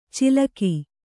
♪ cilaki